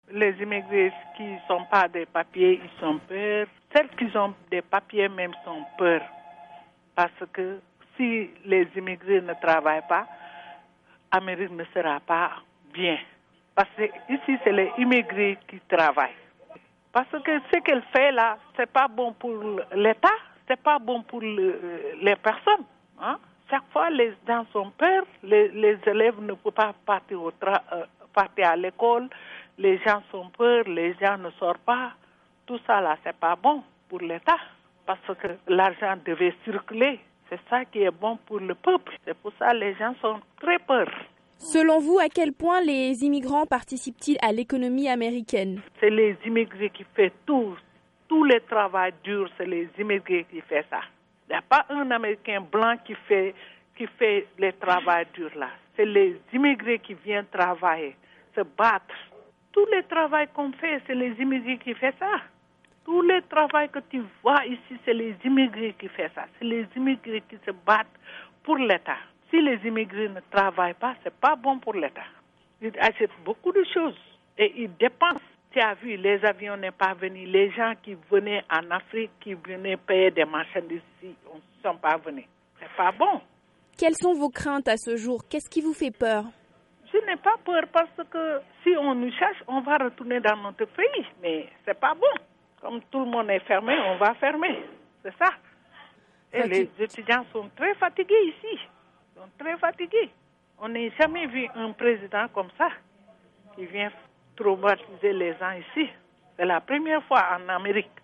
Une restauratrice sénégalaise de New York